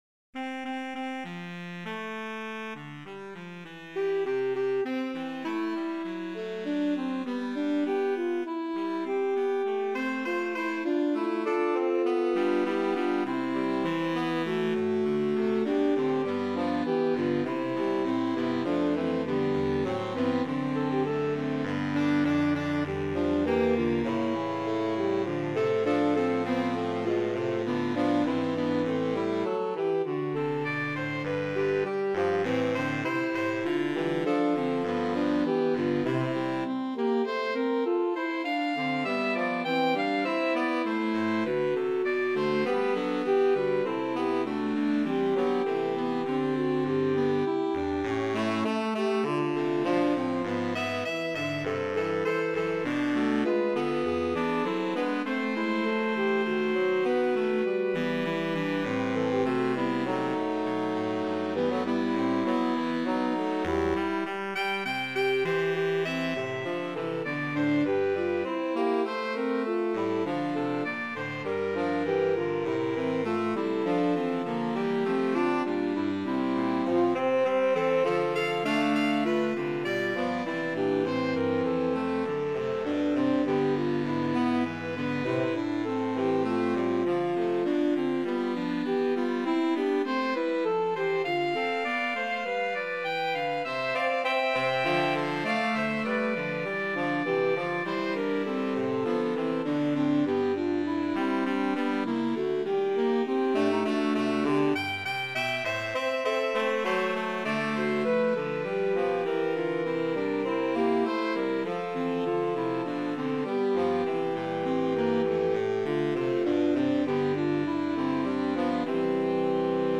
Voicing: Saxophone Quartet (AATB)